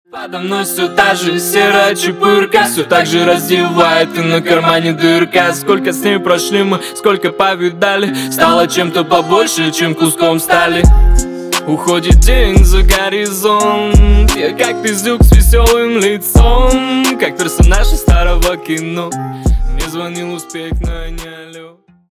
на русском грустные